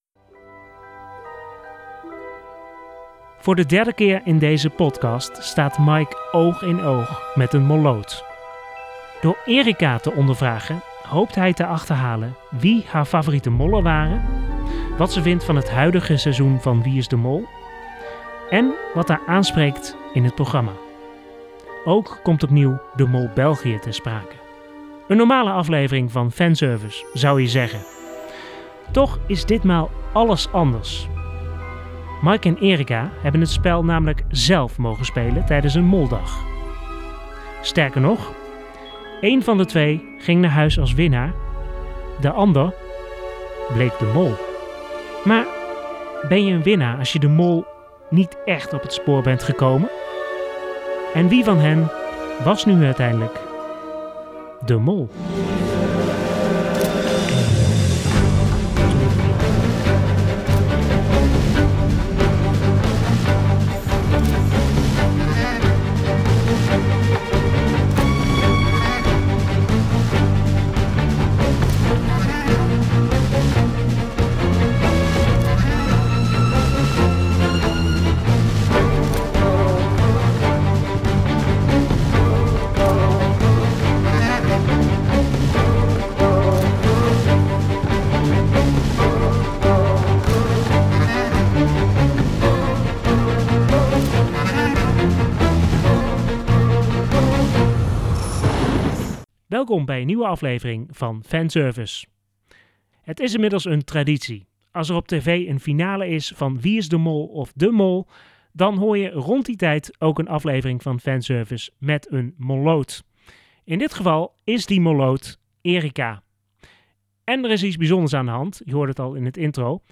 Uiteraard heb ik wederom een molloot te gast rond de bekendmaking van Wie Is De Mol?.
Ditmaal heb ik echter iemand te gast die het spel zélf heeft mogen spelen.